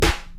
Snare (Gone, Gone).wav